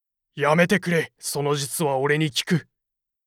パロディ系ボイス素材　2